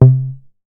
MoogTom 008.WAV